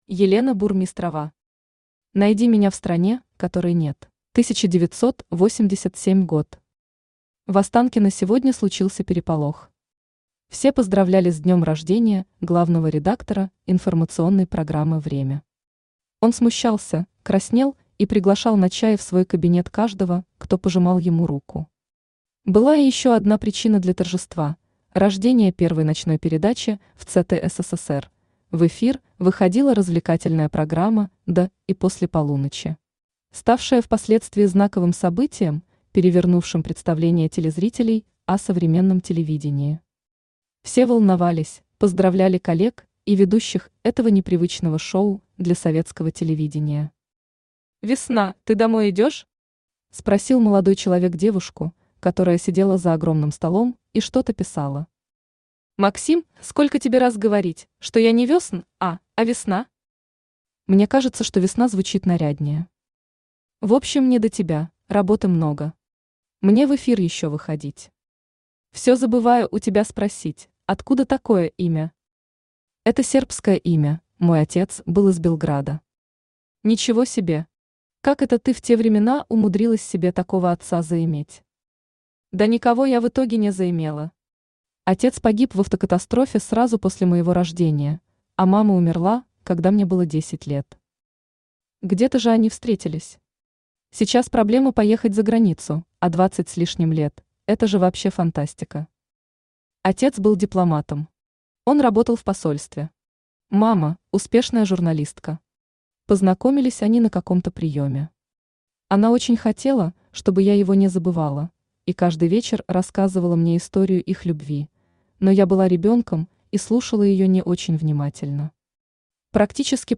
Аудиокнига Найди меня в стране, которой нет | Библиотека аудиокниг
Aудиокнига Найди меня в стране, которой нет Автор Елена Валерьевна Бурмистрова Читает аудиокнигу Авточтец ЛитРес.